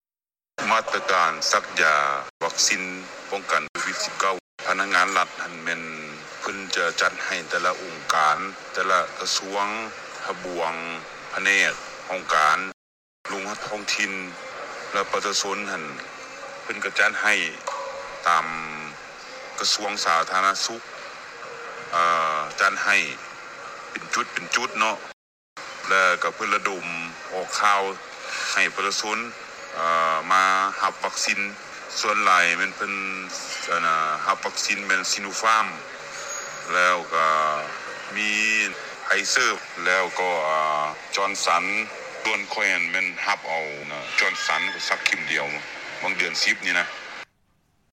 ພະນັກງານລັດຖະກອນ ກ່າວເຖິງການປຸກລະດົມໃຫ້ປະຊາຊົນໄປສັກຢາວັກຊີນຂອງທາງການລາວ